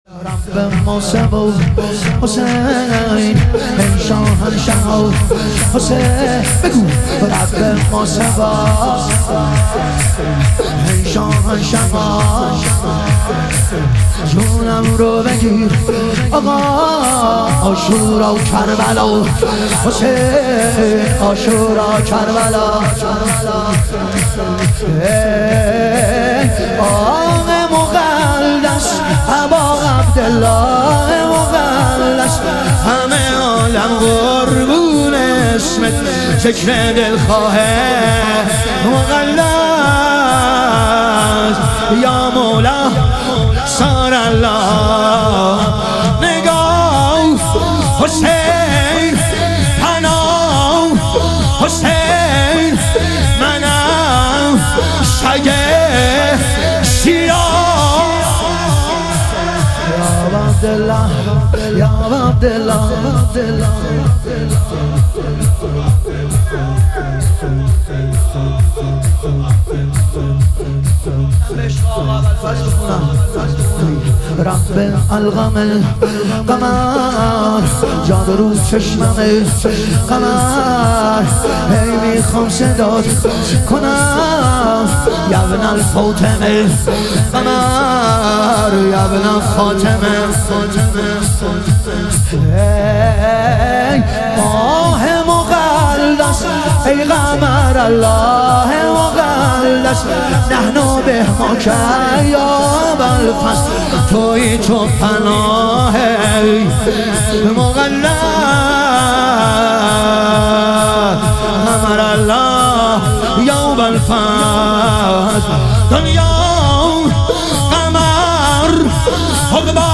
ظهور وجود مقدس حضرت قاسم علیه السلام - شور